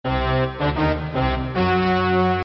Play, download and share Dramatic button original sound button!!!!
dramatic-button.mp3